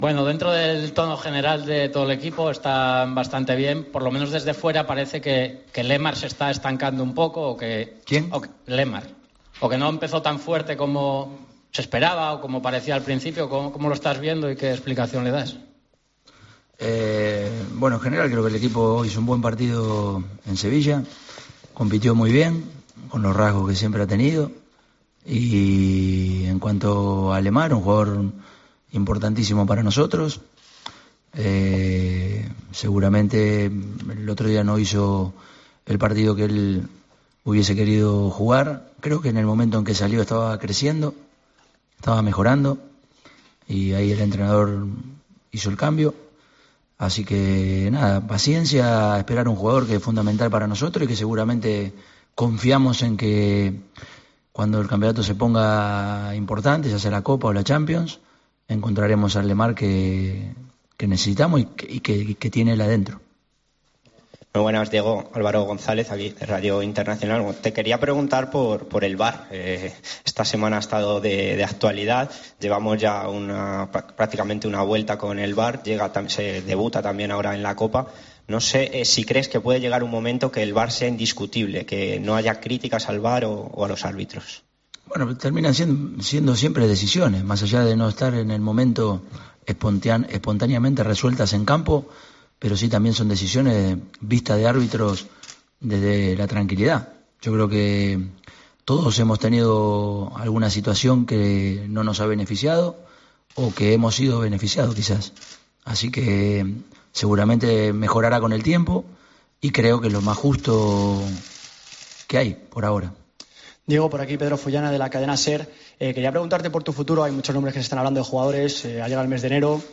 "Seguramente mejorará con el tiempo y creo que es lo mas justo que hay, por ahora"añadió el técnico en rueda de prensa en la Ciudad Deportiva de la localidad madrileña de Majadahonda.